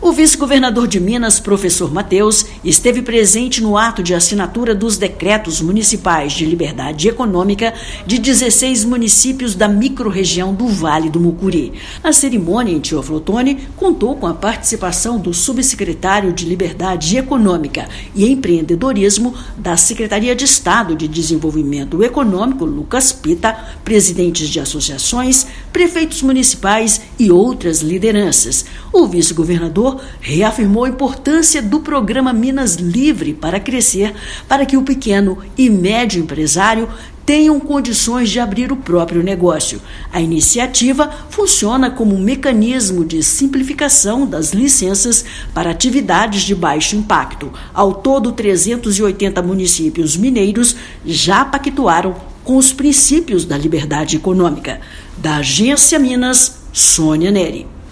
[RÁDIO] Governo de Minas celebra adesão de municípios do Vale do Mucuri ao programa Minas Livre Para Crescer
Ao todo, 380 cidades já assinaram o decreto de liberdade econômica; marca representa 43% do estado. Ouça matéria de rádio.